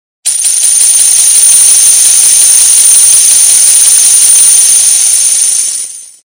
gold_rain.mp3